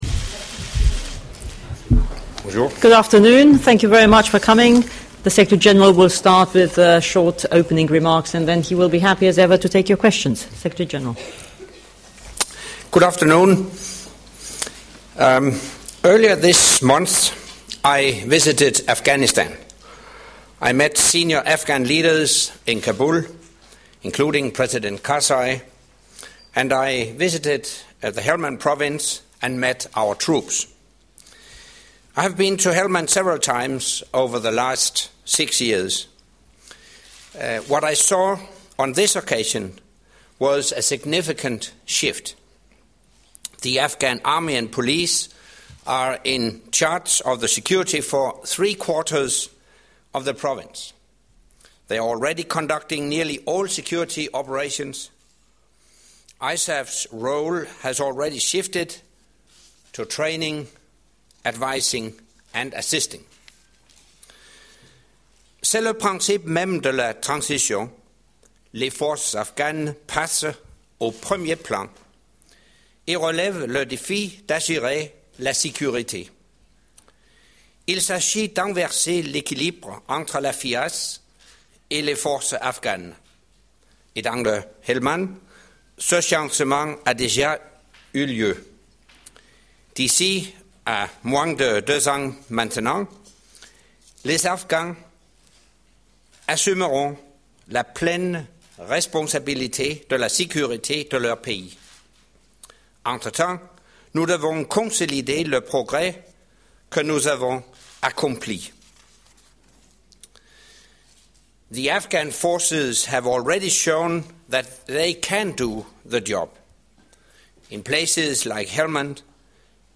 NATO Secretary General Anders Fogh Rasmussen gave an update on NATO’s Afghan mission and his recent visit to Helmand province during his monthly press conference held at NATO headquarters on Monday 18 March 2013.